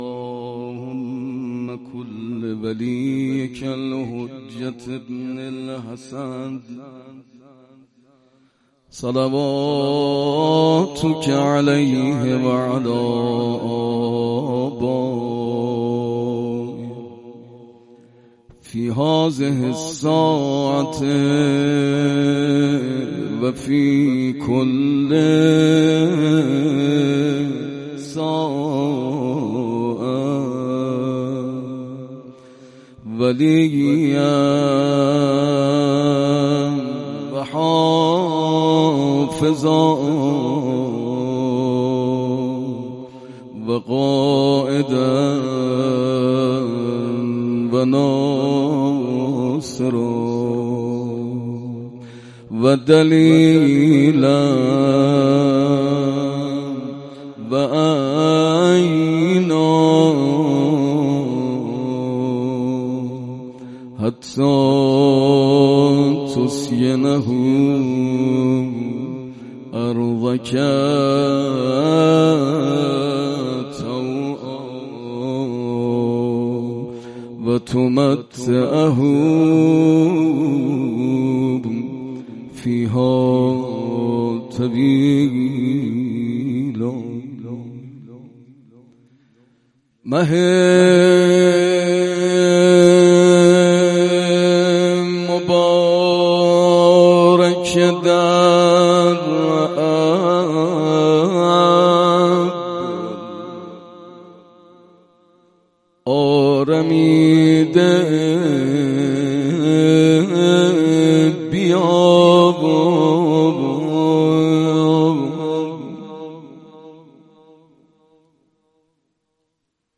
مناجات و روضه.mp3
مناجات-و-روضه.mp3